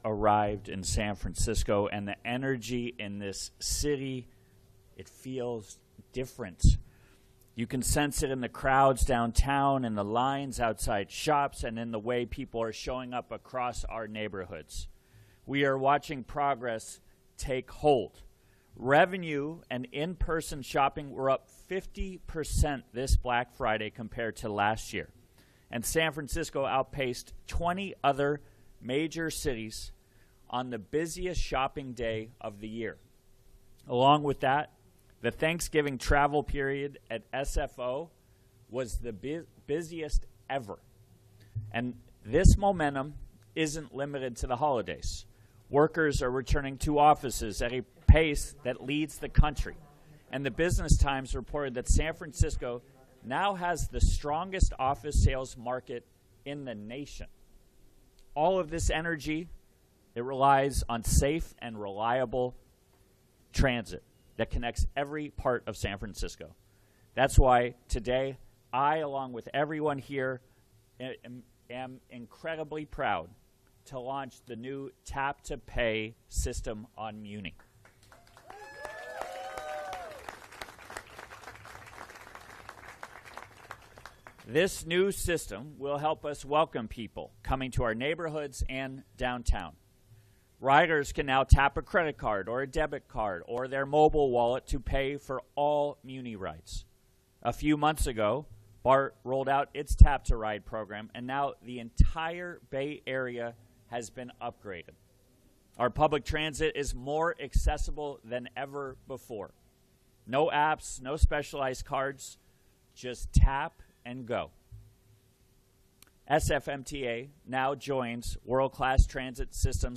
Mayor's Press Conference Audio